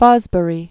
Fos·bur·y
(fŏzbə-rē), Richard Douglas Known as "Dick."